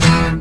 courvousierriff.wav